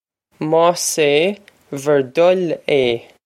Pronunciation for how to say
Maws ay vur duh-il ay
This is an approximate phonetic pronunciation of the phrase.